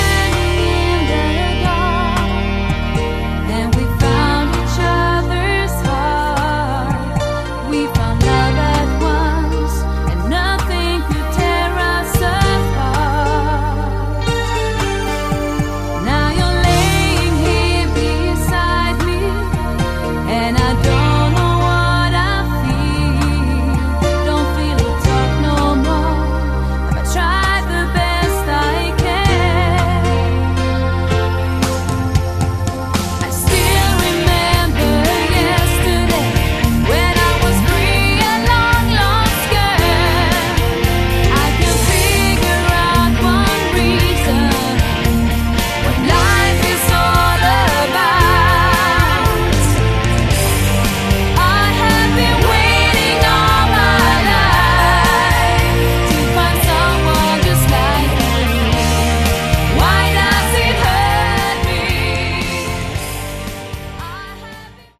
Category: AOR/Melodic Rock
Vocals
Guitars
Bass
Drums, Keyboards